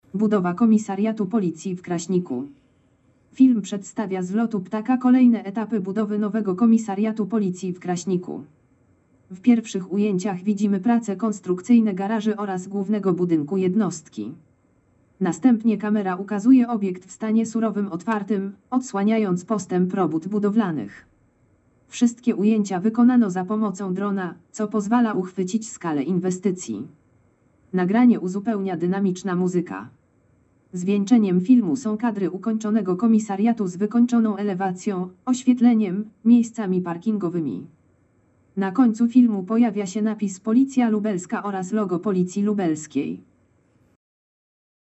Nagranie audio Audiodeskrypcja filmu z nowego Komisariatu Policji w Kraśniku